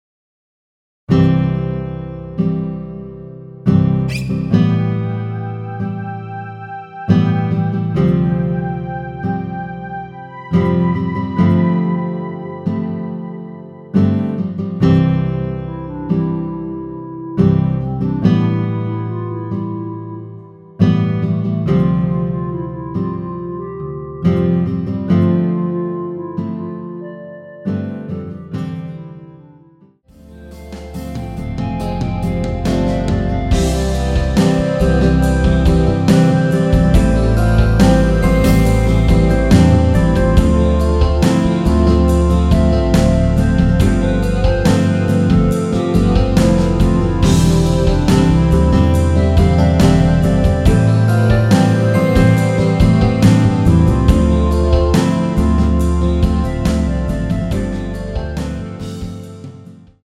원키에서(-3)내린 멜로디 포함된 MR 입니다.
앞부분30초, 뒷부분30초씩 편집해서 올려 드리고 있습니다.
중간에 음이 끈어지고 다시 나오는 이유는